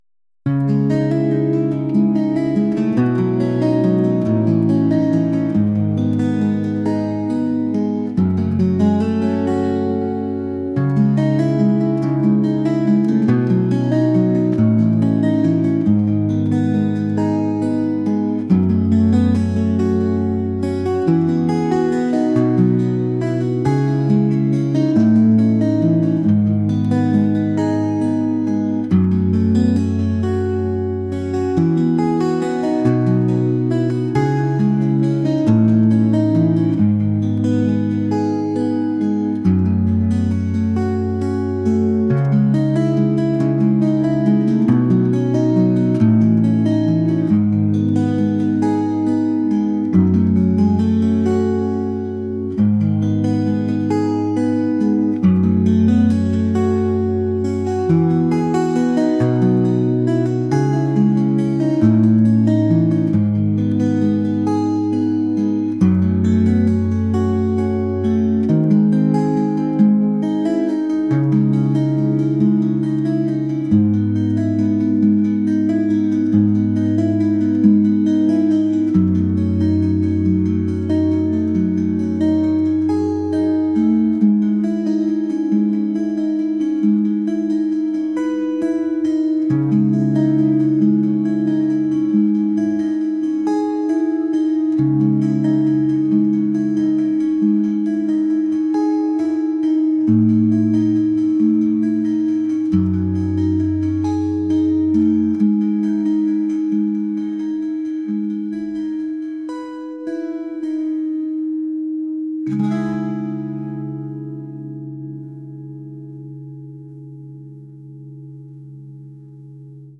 folk | ambient | cinematic